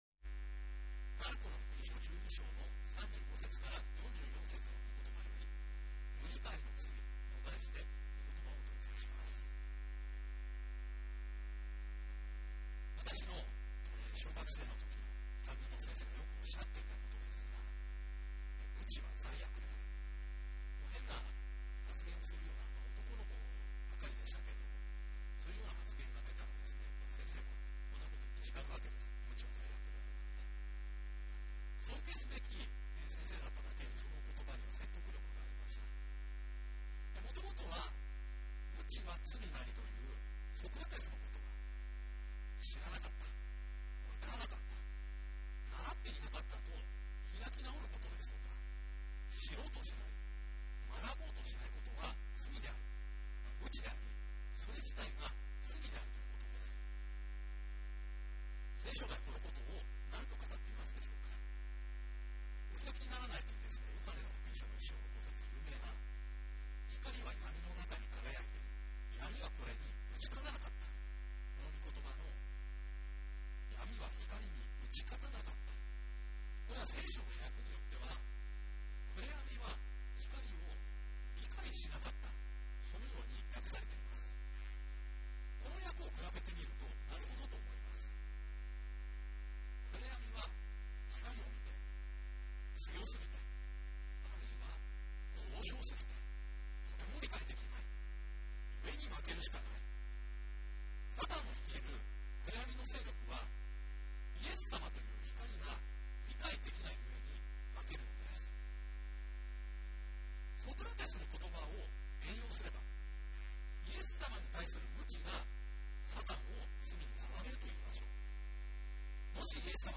2023年10月22日_水戸第一バプテスト教会マルコの福音書12章35節～44節「無理解の罪」 | ベテスダ・柏 - 楽天ブログ